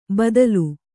♪ badau